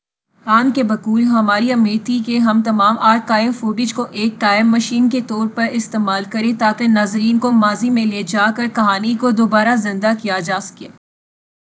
Spoofed_TTS/Speaker_04/276.wav · CSALT/deepfake_detection_dataset_urdu at main